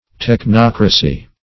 technocracy \tech*noc"ra*cy\, n.